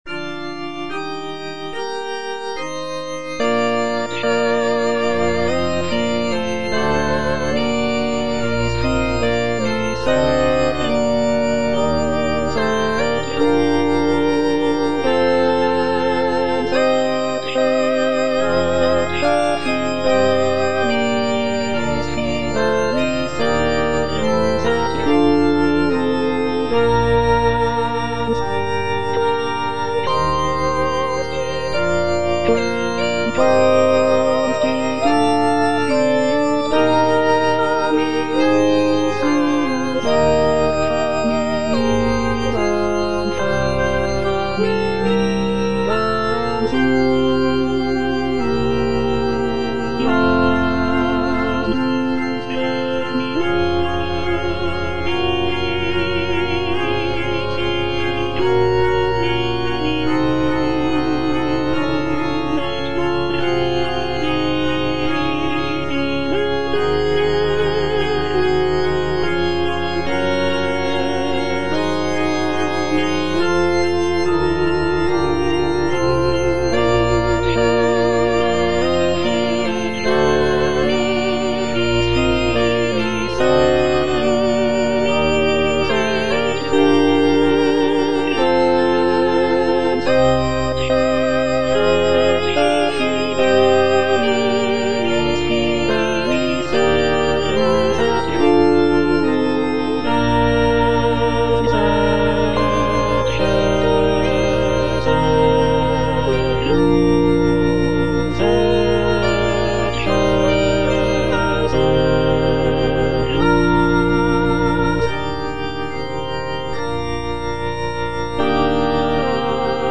G. FAURÉ - ECCE FIDELIS SERVUS (All voices) Ads stop:
"Ecce fidelis servus" is a sacred choral work composed by Gabriel Fauré in 1896. The piece is written for four-part mixed choir and organ, and is based on a biblical text from the Book of Matthew. The work is characterized by Fauré's trademark lyrical melodies and lush harmonies, creating a serene and contemplative atmosphere.